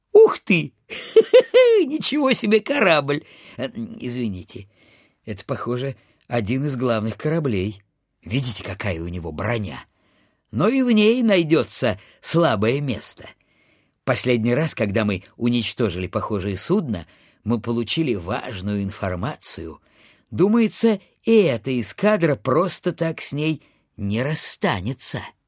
Примеры озвучания: